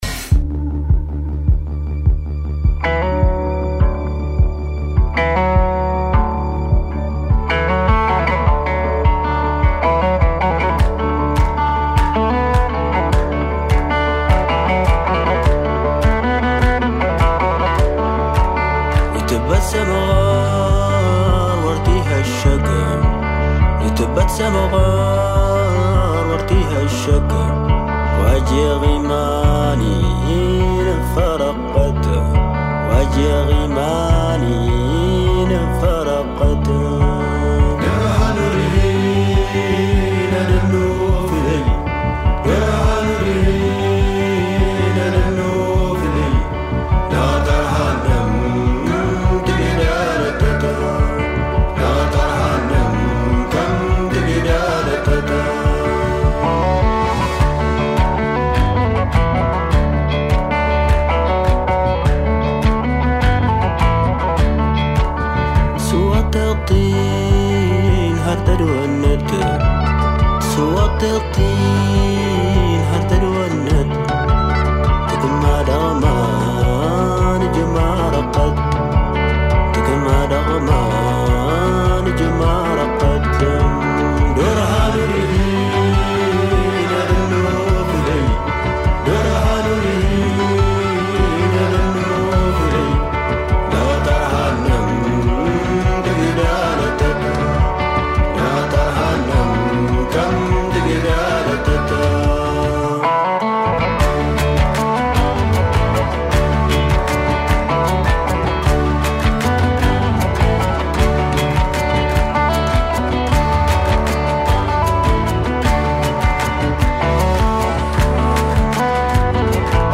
La musica come unico raccordo capace di intrecciare più racconti.